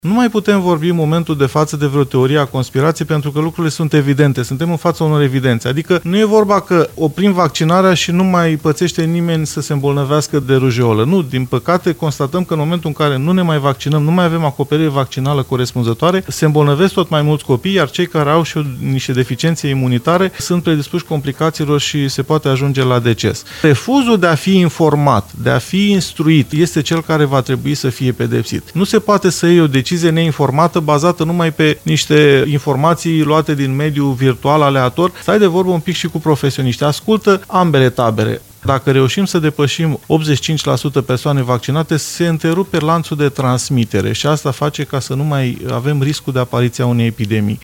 El a declarat, la Radio VIVA FM, că Ministerul lucrează la un proiect de lege prin care să fie sancționați părinții care refuză informarea referitoare la pericolele nevaccinării.